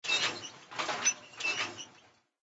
ENC_cogside_step.ogg